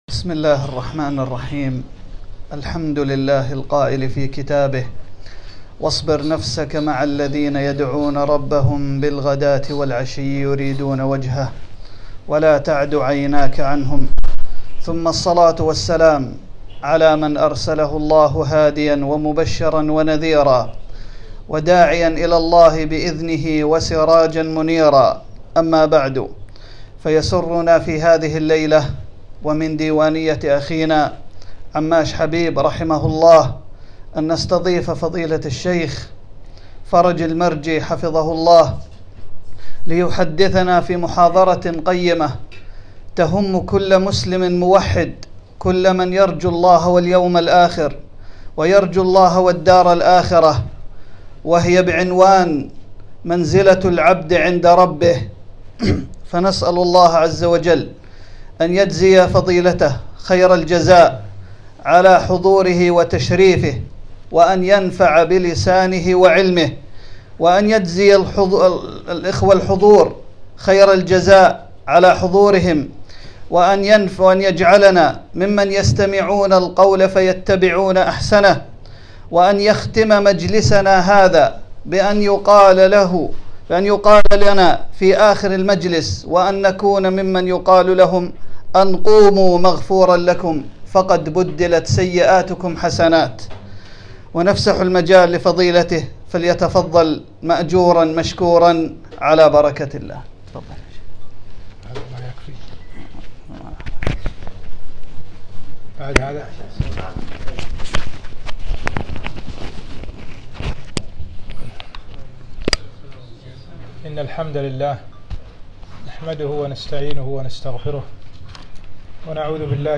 محاضرة - منزلة العبد عند ربه